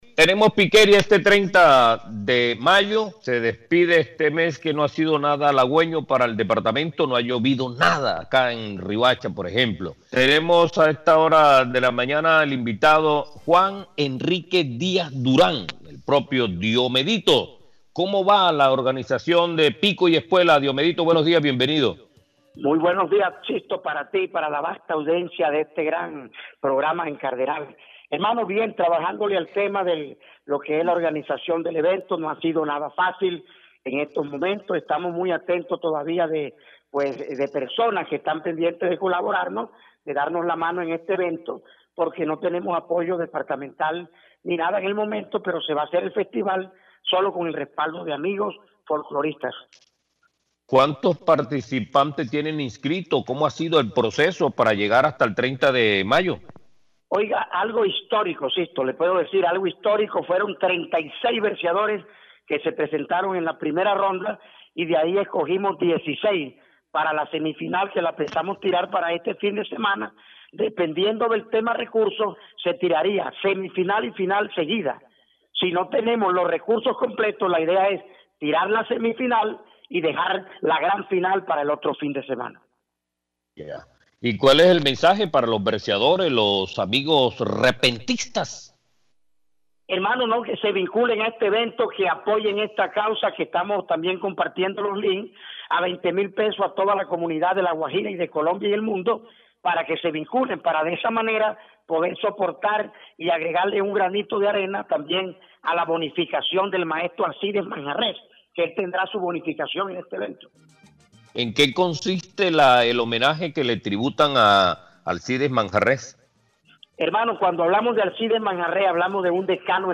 En conversación con Cardenal Stereo, señaló que el festival se realiza sin el apoyo gubernamental.